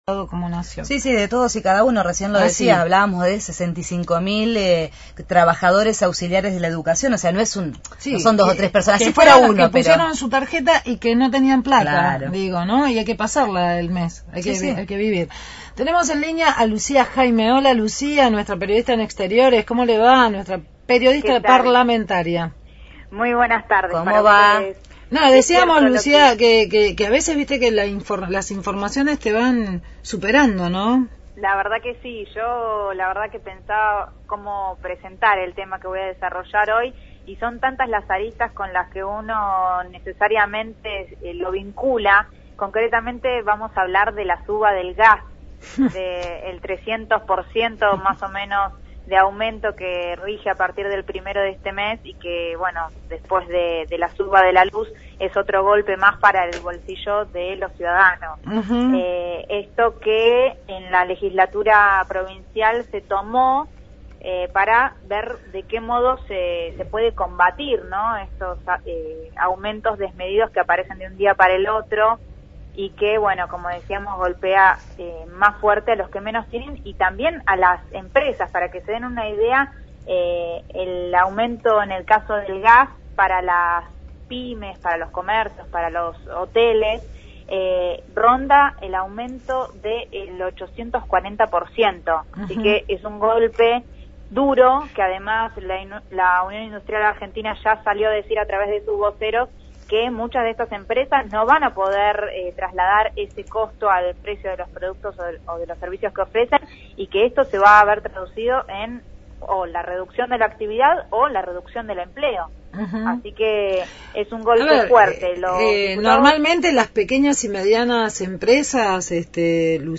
Móvil/ Diputados del FpV contra el tarifazo – Radio Universidad